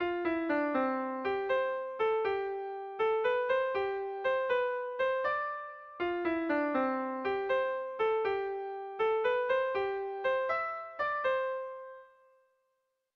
Gabonetakoa
Lau puntuko berdina, 8 silabaz